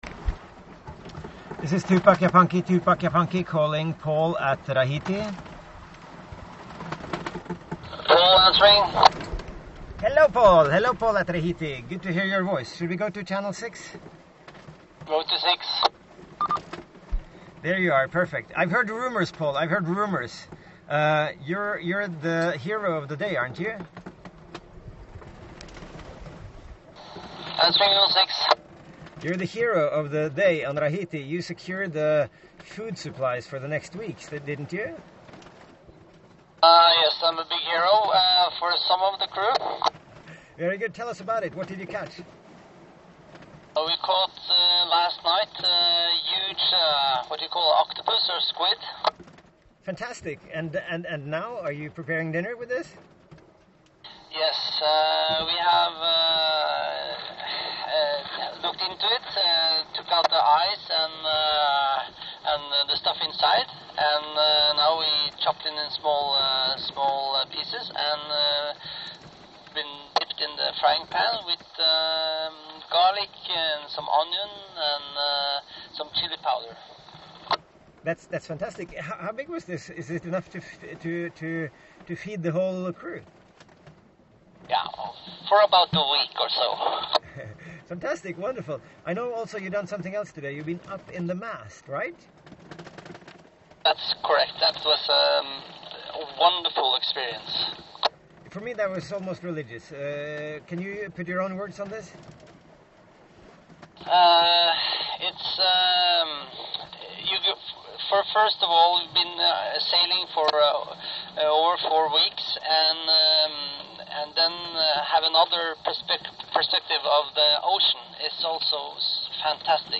However, we do have an authentic VHF conversation which describes the incident.